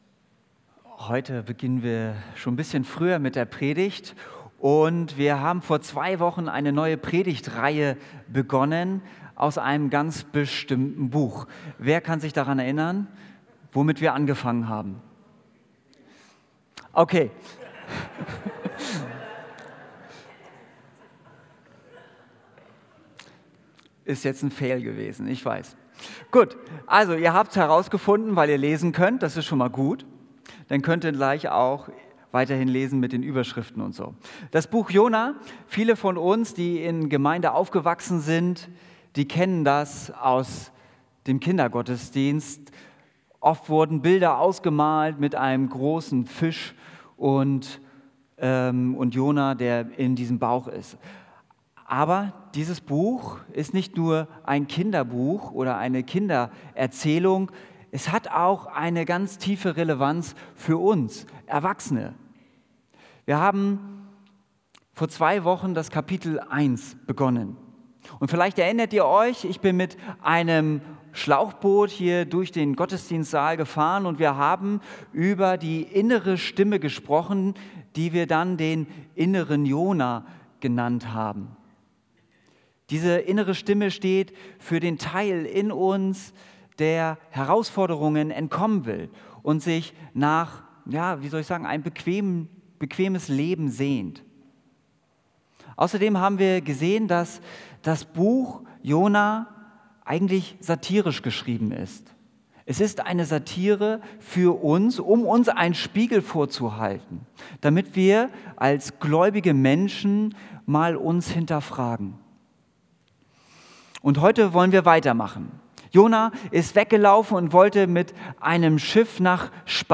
Predigt Rettung in der Tiefe